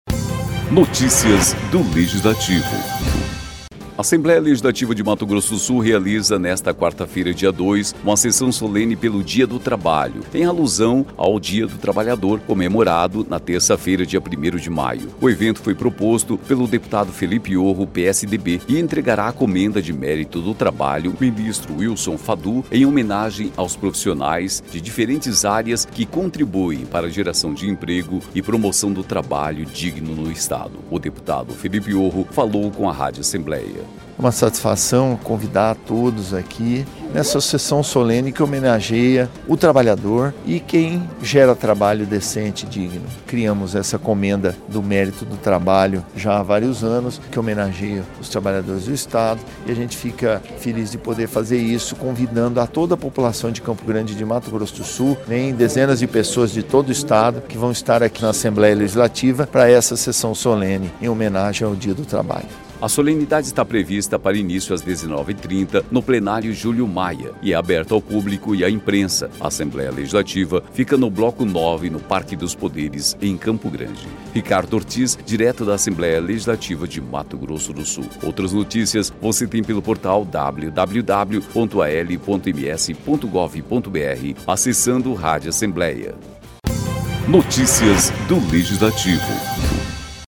Equipe Rádio Assembleia em 02/05/2018 12:15:00